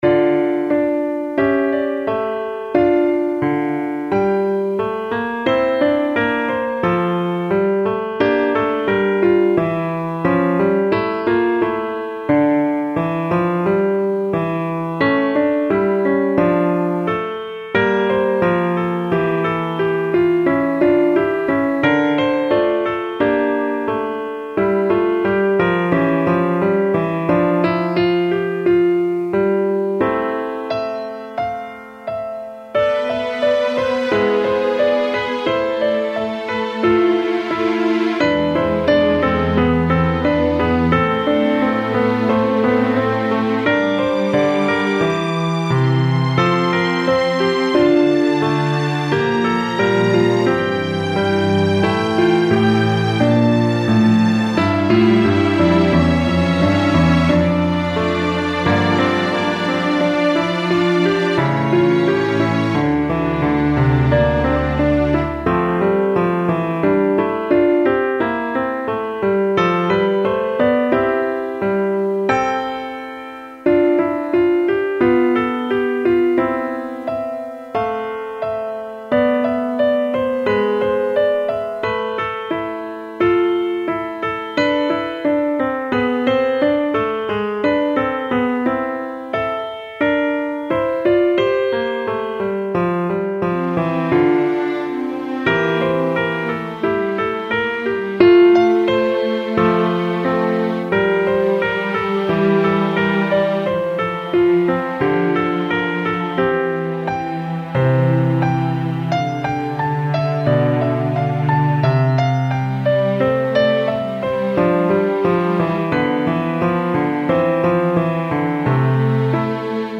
ballade - espoir - romantique